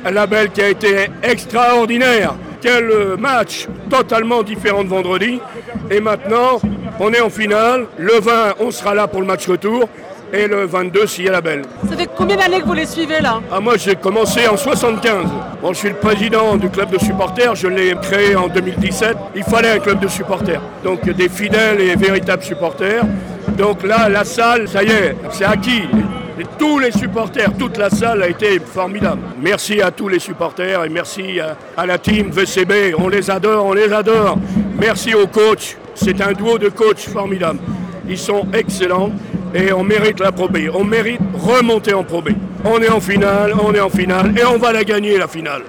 Podcast reportages